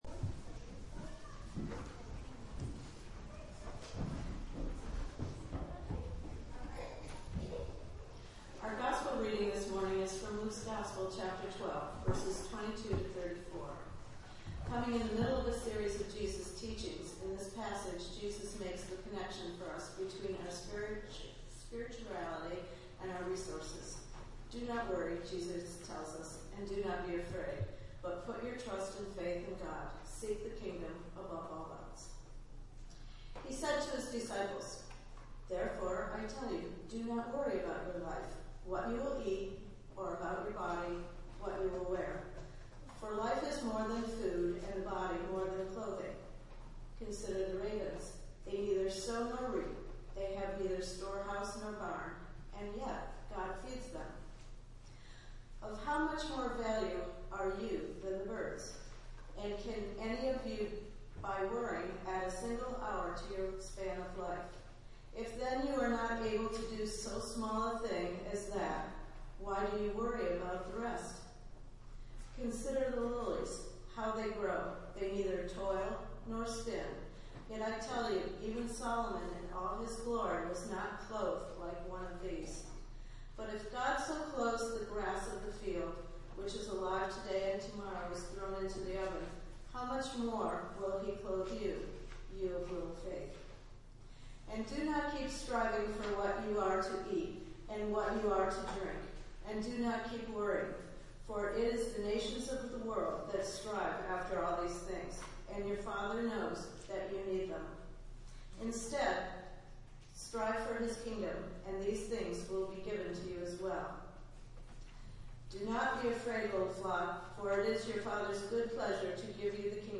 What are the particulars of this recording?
Date: November 14th, 2010 (Pentecost 25) – Consecration Sunday Delivered at: The United Church of Underhill